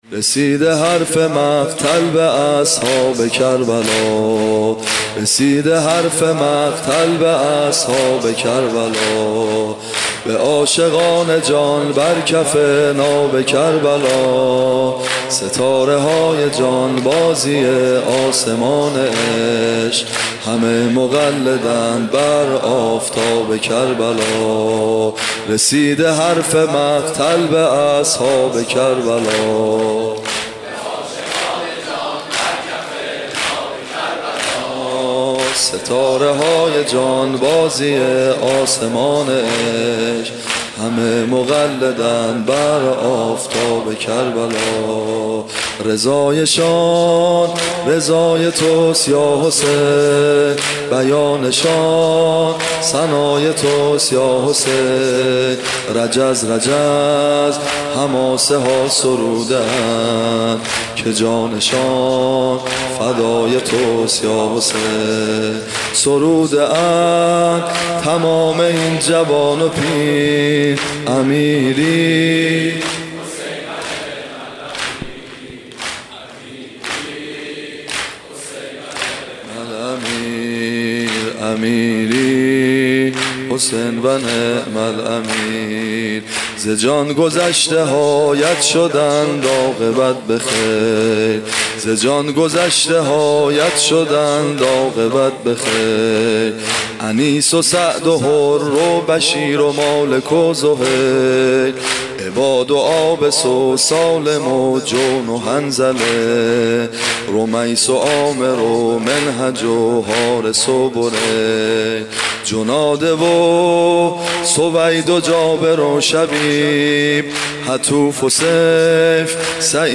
صوت مراسم شب چهارم محرم 1438هیئت ابن الرضا(ع) ذیلاً می‌آید: